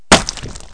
decapit.mp3